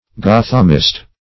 Gothamist \Go"tham*ist\